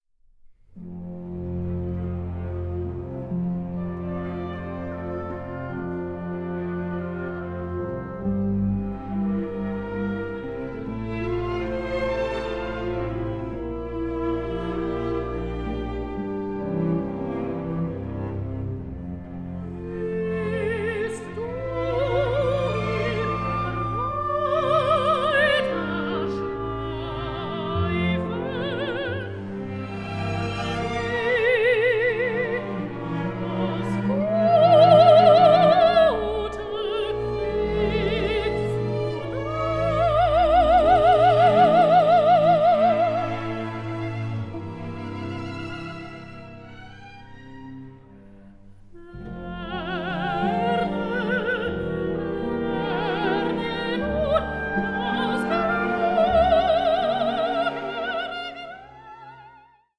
Songs for voice and orchestra
soprano